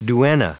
Prononciation du mot duenna en anglais (fichier audio)
Prononciation du mot : duenna